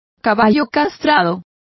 Complete with pronunciation of the translation of geldings.